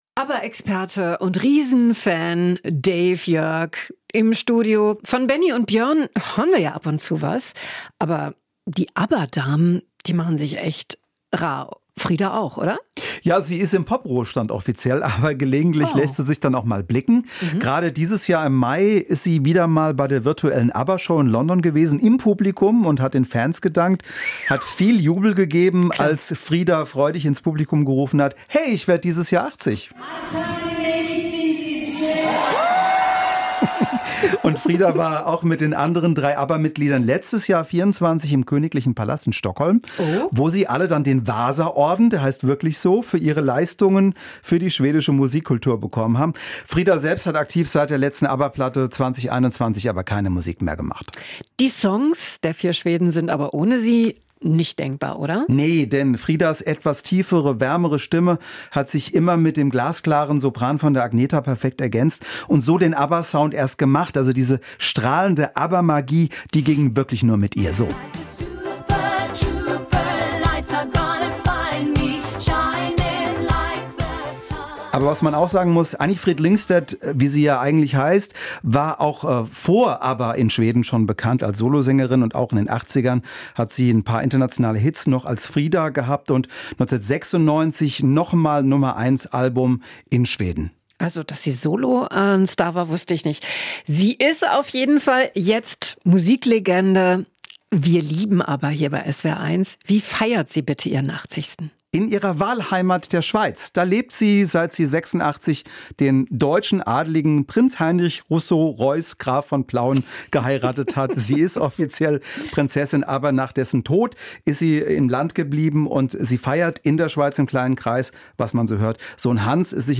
Das Gespräch führte